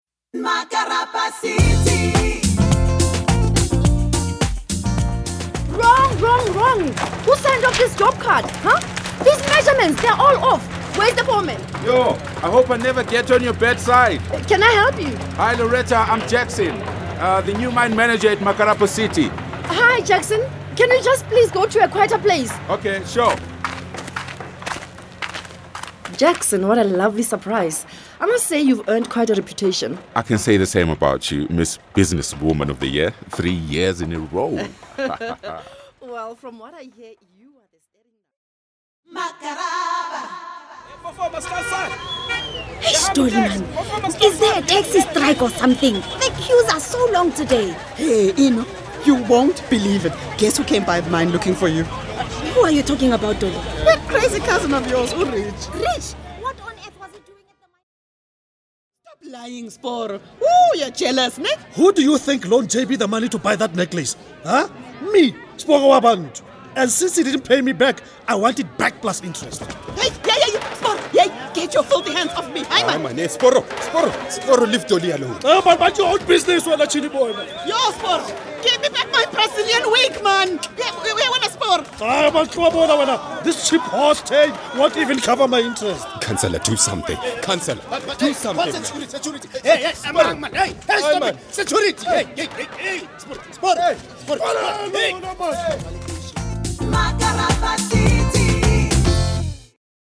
(Radio Drama Series)